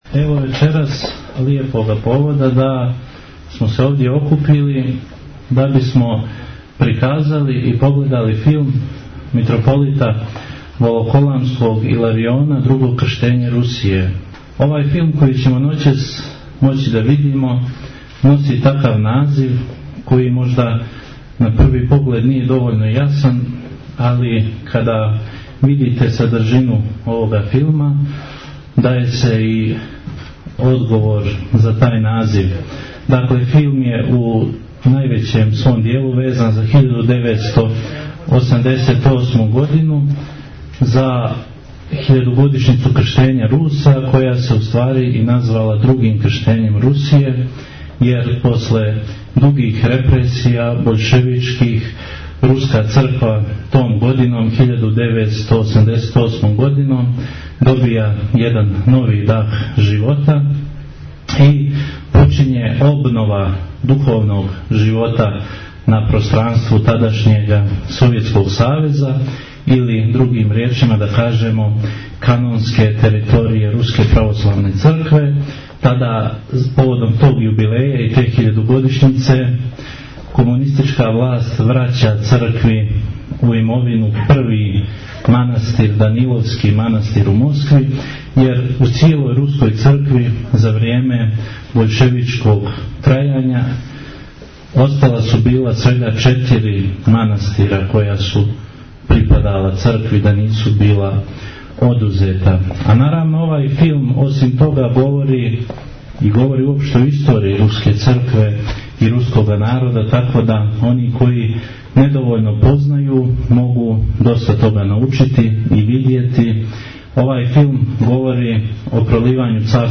пред публиком у Никшићу